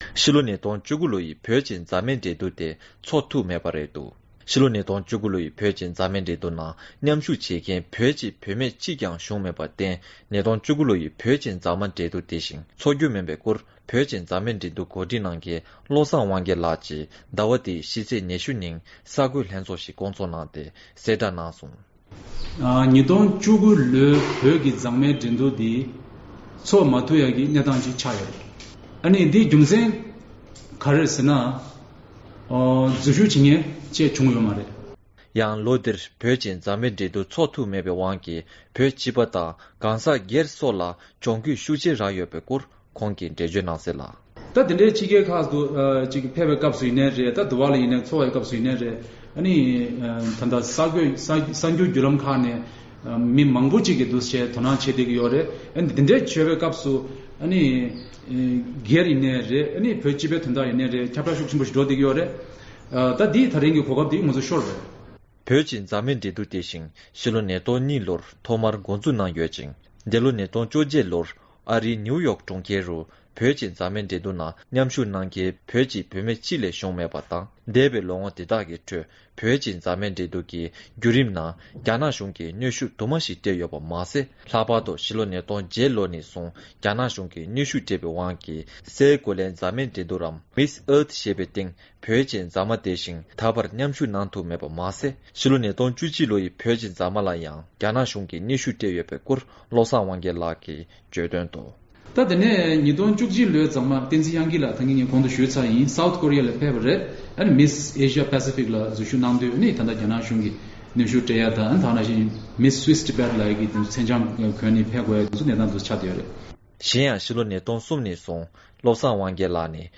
རྡ་རམ་ས་ལ་ནས་བཏང་བའི་གནས་ཚུལ